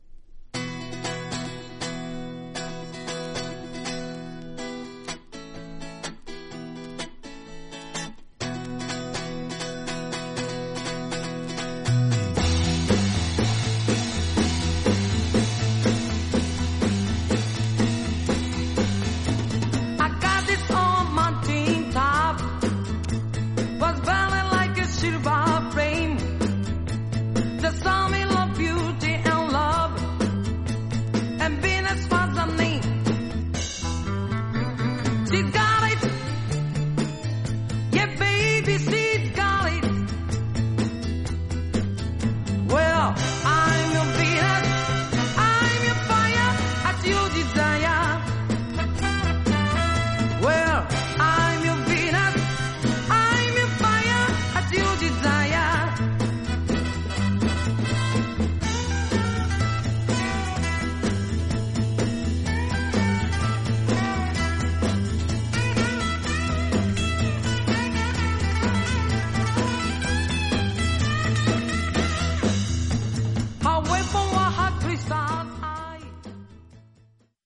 盤面、どれも音には影響ないと思いますが薄いスリキズが多めです。
実際のレコードからのサンプル↓ 試聴はこちら： サンプル≪mp3≫